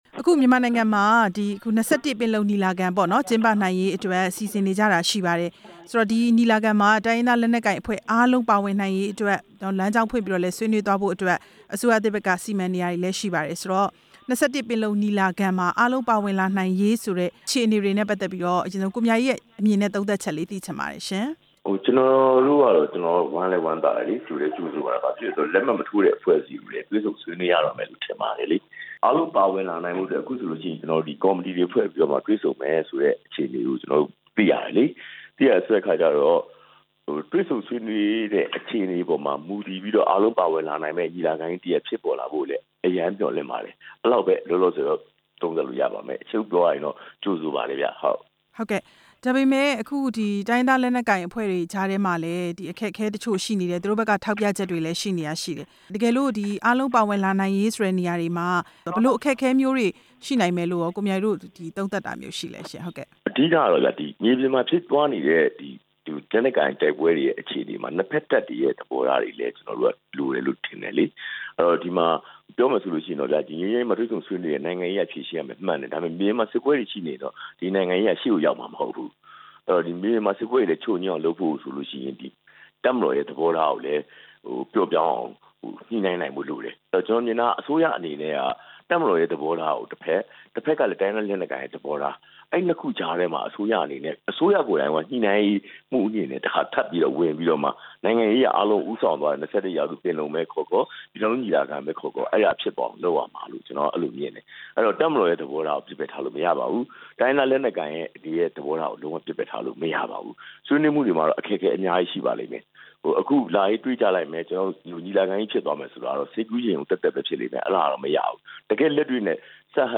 ၂၁ ရာစု ပင်လုံညီလာခံ အောင်မြင်ရေး၊ ကိုမြအေးနဲ့ မေးမြန်းချက်